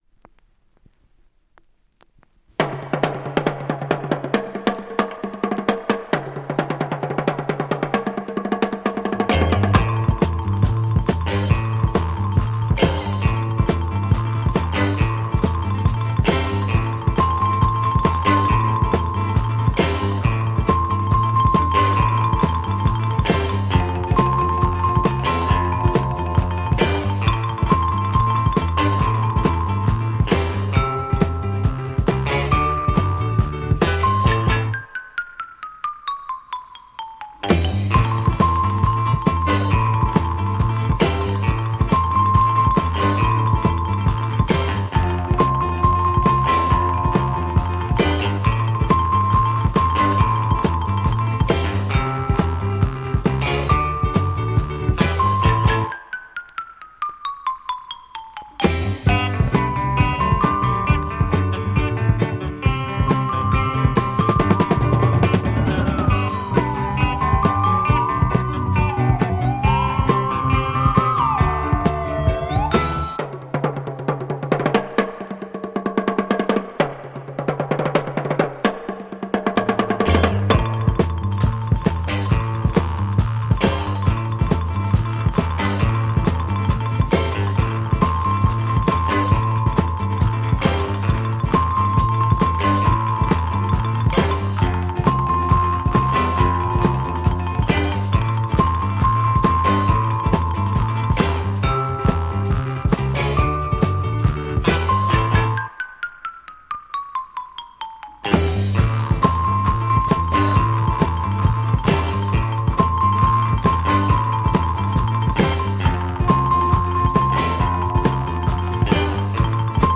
Instru Orig 1972?